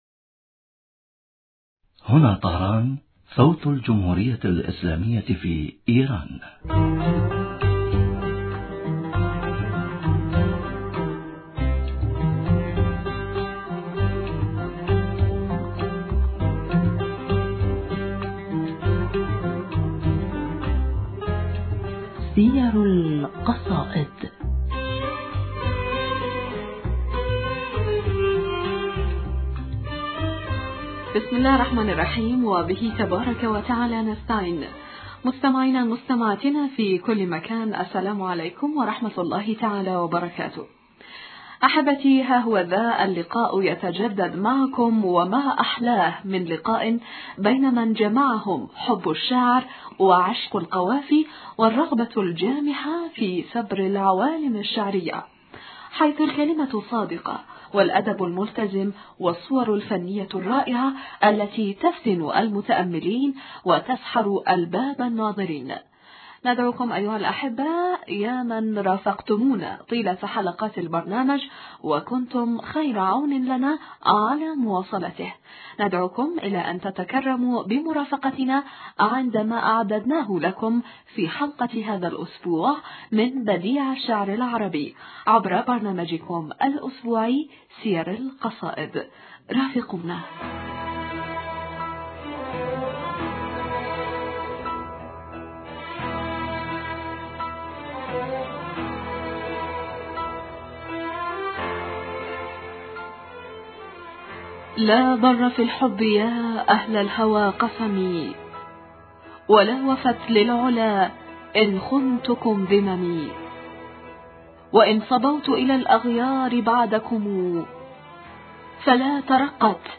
المحاورة